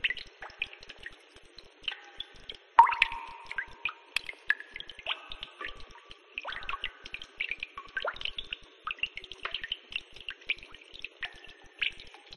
Drips.ogg